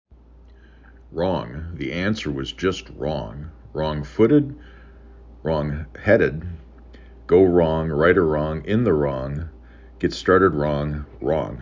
5 Letters, 1 Syllable
3 Phonemes
r aw N